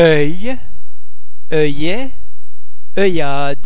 eu oeu oe ue vowel-base simimlar to ir in ( sir)
The French [ ir ] [ er ] sound is similar to the vowel sound in English words like sir or herd. To be produced with almost no lip-rounding.